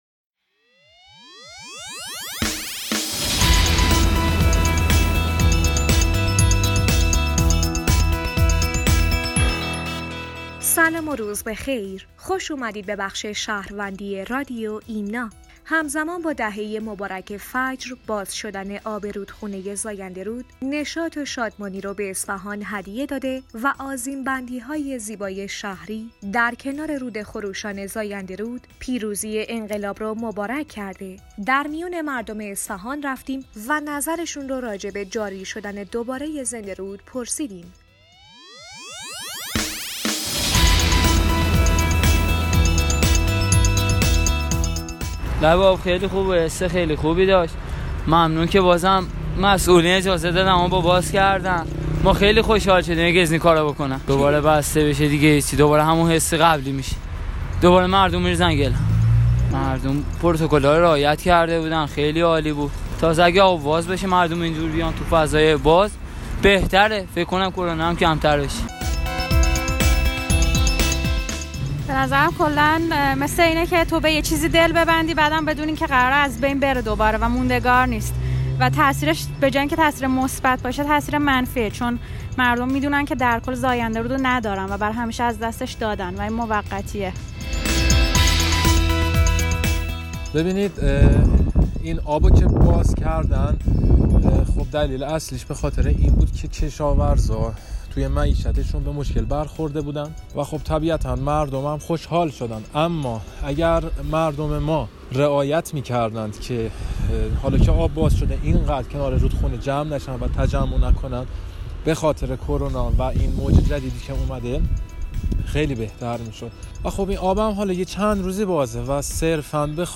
همزمان با دهه مبارک فجر، باز شدن آب رودخانه زاینده رود، نشاط و شادمانی را به اصفهان هدیه داده و آذین بندی‌های زیبای شهر در کنار رود خروشان زاینده رود پیروزی انقلاب را مبارک کرده است. در میان مردم اصفهان رفتیم و نظرشان را راجع به جاری شدن دوباره زنده رود پرسیدیم.